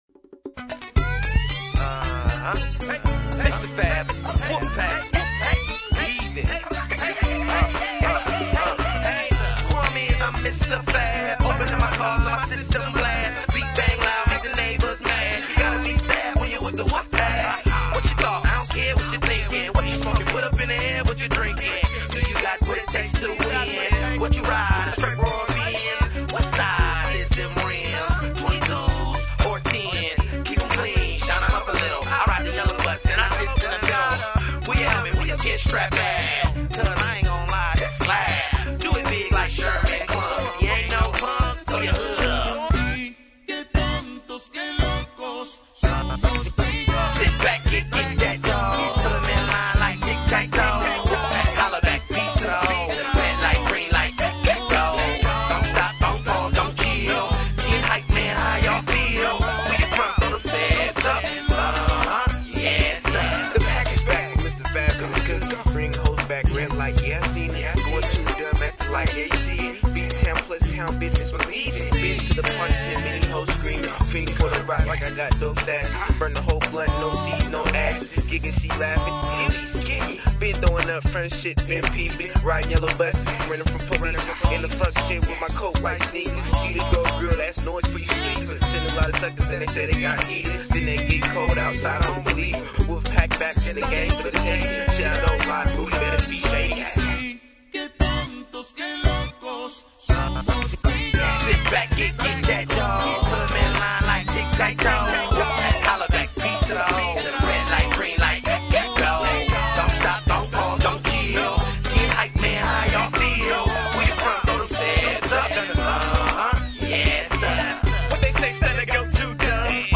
Filed under: Mashup | Comments (2)